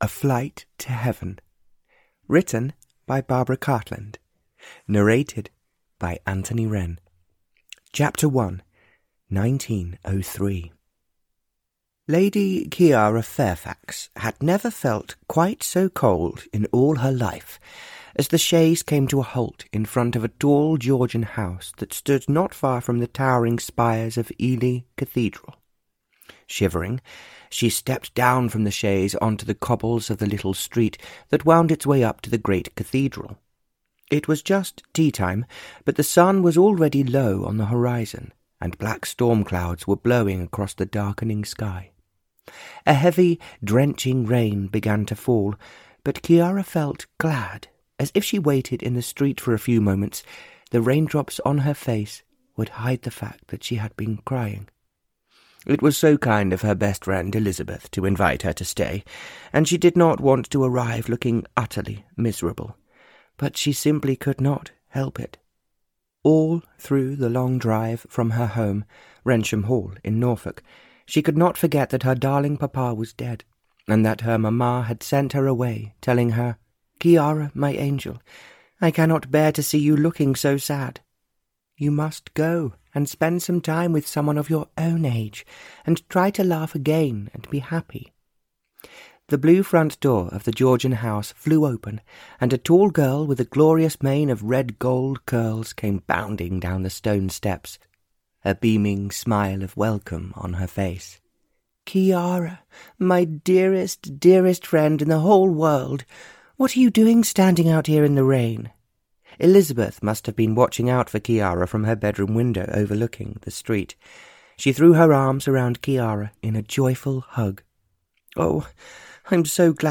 A Flight to Heaven (Barbara Cartland's Pink Collection 102) (EN) audiokniha
Ukázka z knihy